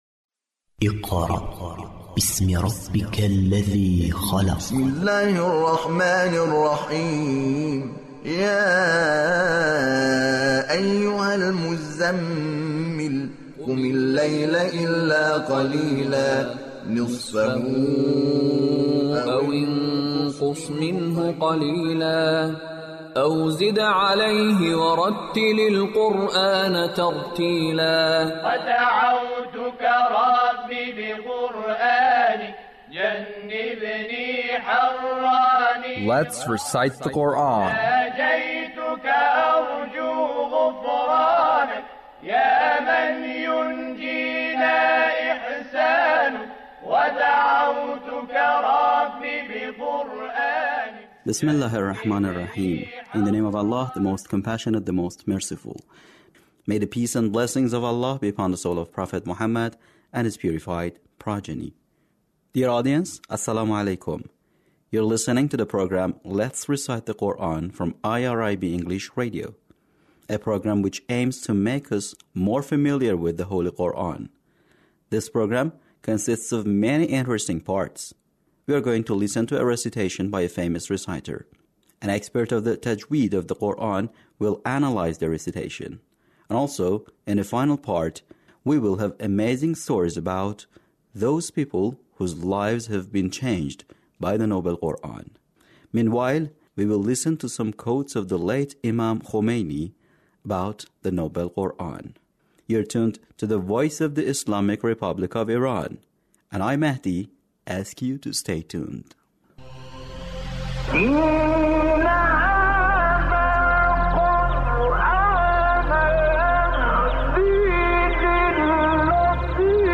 Recitation of surah Qadr, al-Ikhlas and al-Falaq Yunus - Attractiveness of the Noble Quran